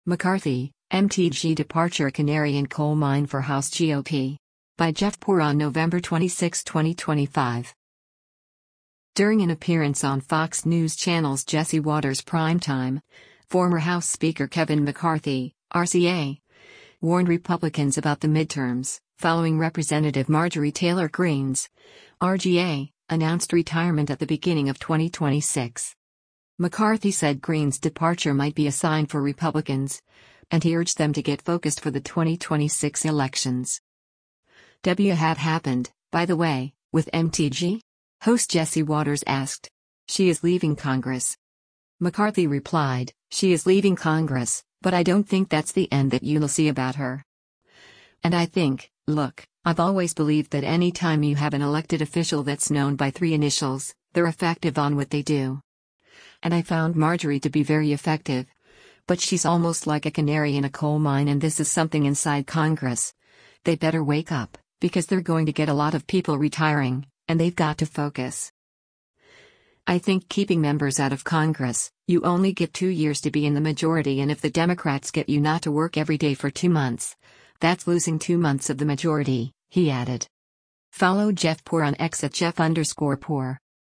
During an appearance on Fox News Channel’s “Jesse Watters Primetime,” former House Speaker Kevin McCarthy (R-CA) warned Republicans about the midterms, following Rep. Marjorie Taylor Greene’s (R-GA) announced retirement at the beginning of 2026.